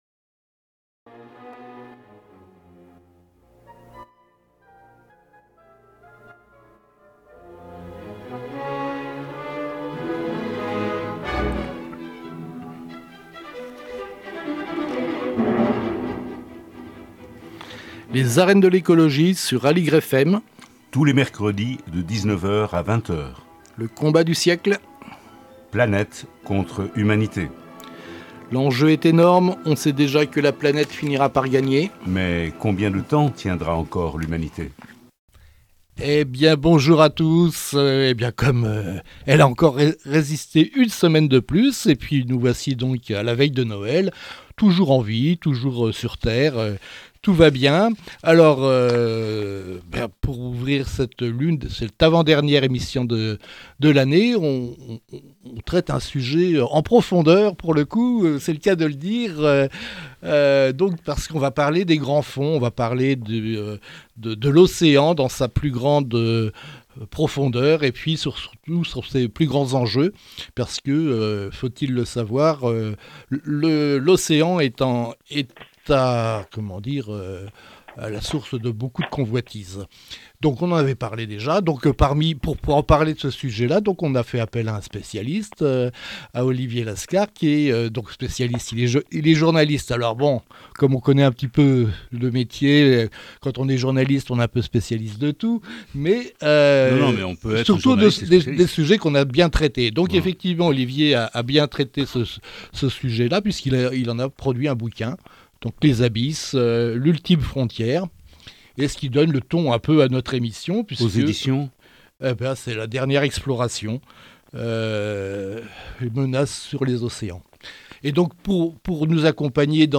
Les arènes de l'écologie, tous les mercredis de 19:00 à 20:00 sur Aligre FM.